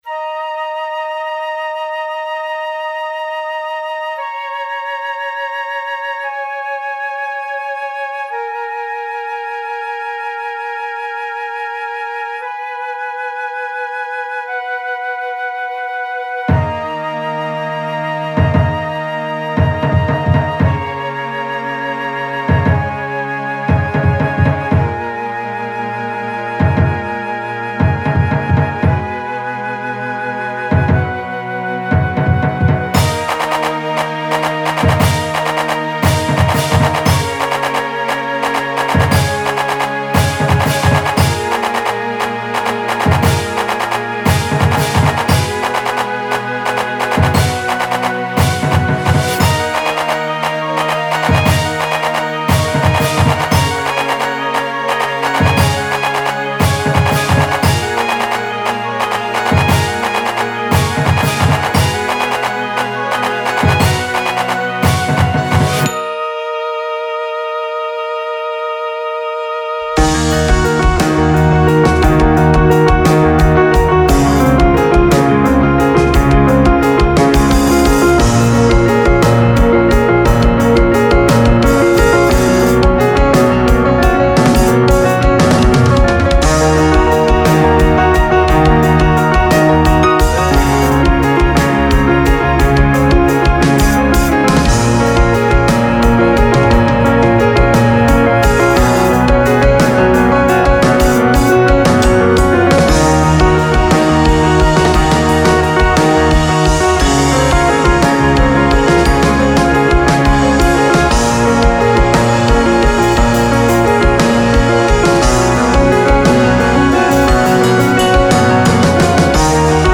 Piano, Bass, Drums, Orchestration
Guitar